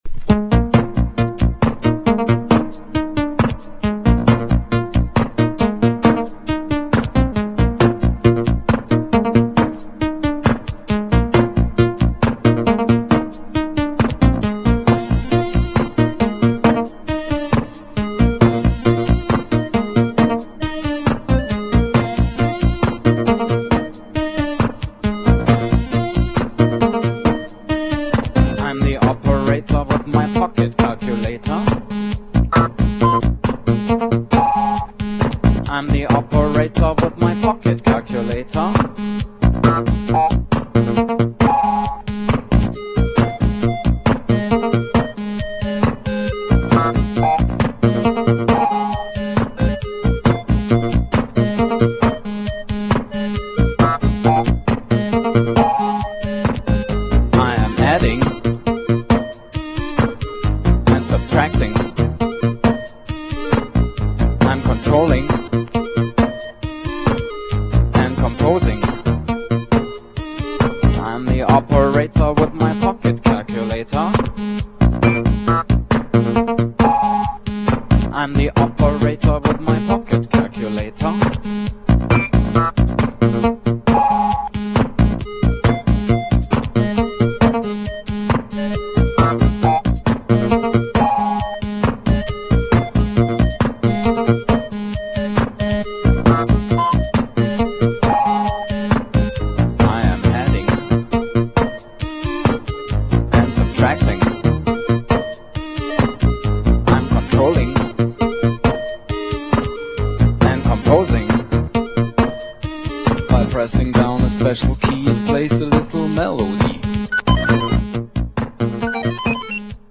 GENRE Dance Classic
BPM 136〜140BPM
# INSTRUMENTAL
# コズミック # ダンサー向け